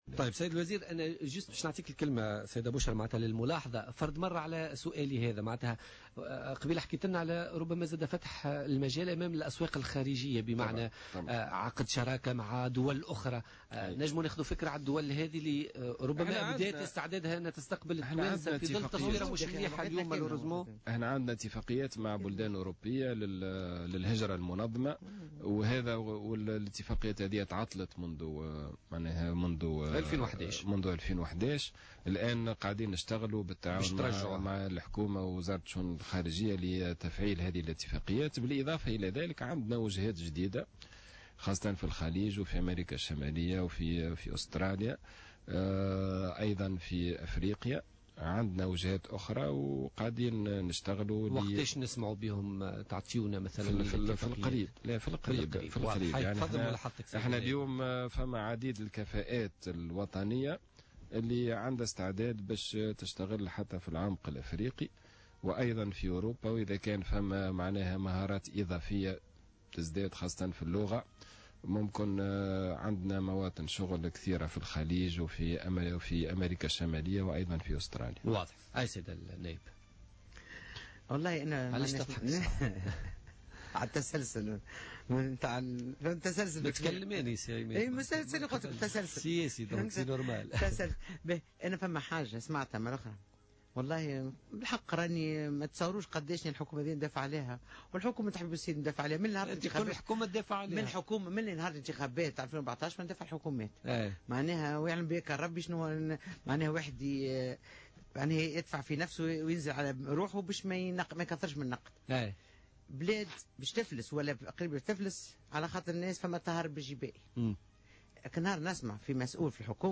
قال وزير التكوين المهني والتشغيل عماد الحمامي خلال استضافته اليوم في برنامج "بوليتكا".